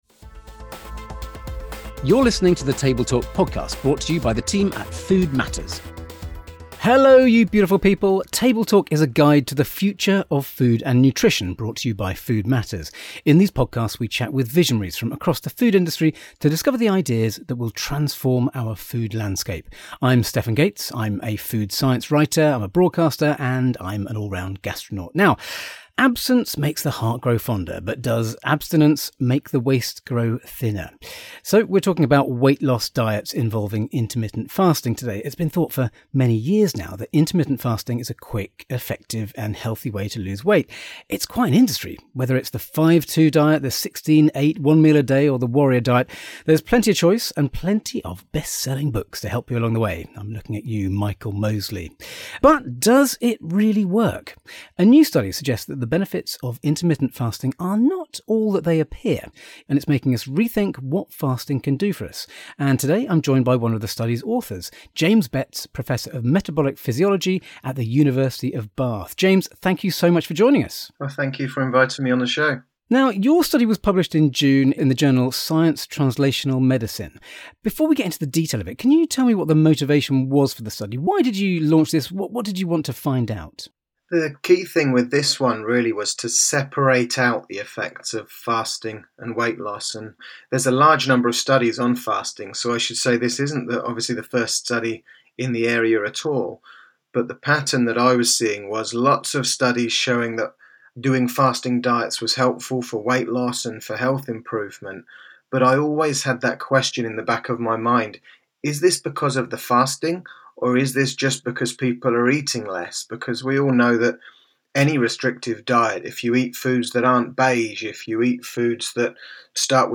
Join the conversation on Table Talk.